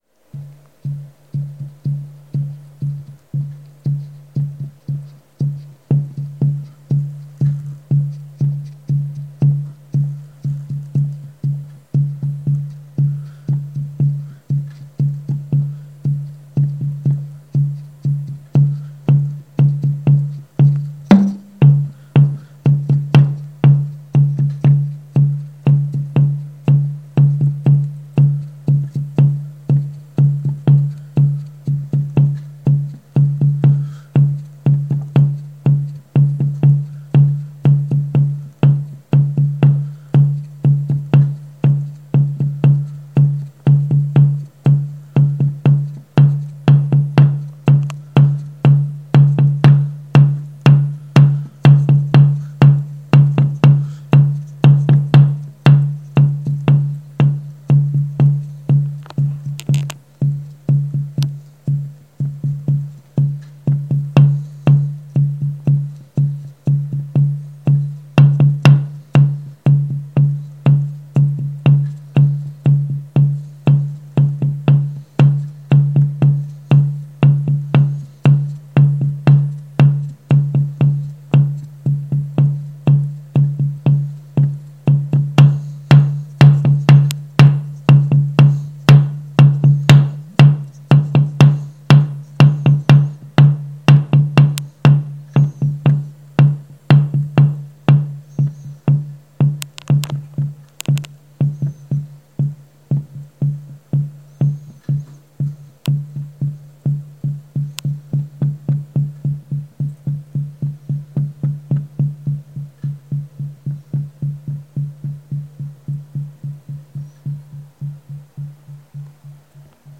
描述：made by supercollider
标签： nature acqua supercollider water
声道立体声